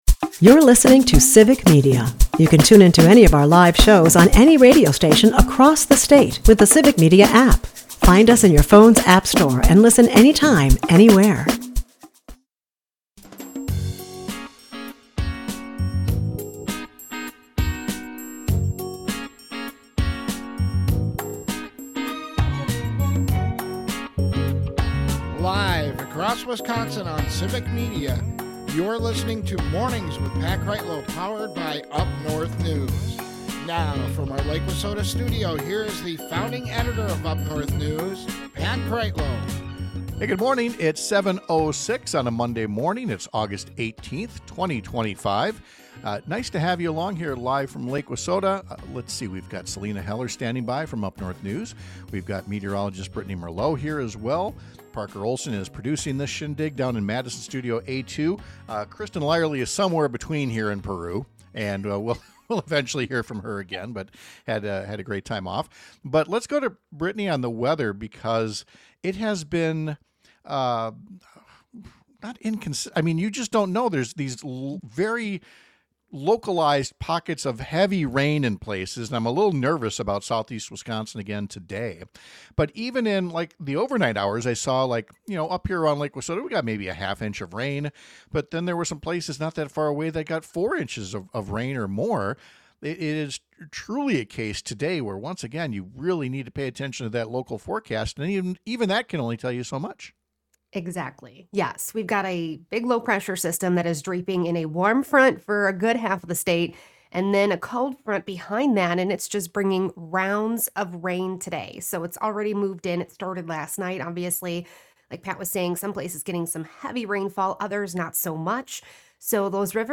Wisconsin is unlike many states that allow ordinary citizens to collect signatures and put ideas on a statewide ballot for new laws or constitutional amendments. We’ll talk to two guests who recently wrote an opinion column outlining why we should have that kind of setup in our state, too.